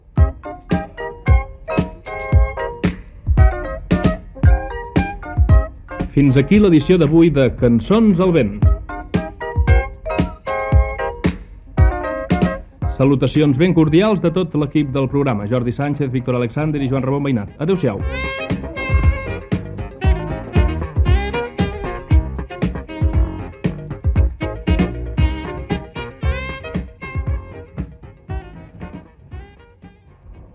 Comiat del programa, amb els noms de l'equip
Musical
FM